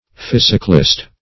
physoclist - definition of physoclist - synonyms, pronunciation, spelling from Free Dictionary Search Result for " physoclist" : The Collaborative International Dictionary of English v.0.48: Physoclist \Phys"o*clist\, n. (Zool.)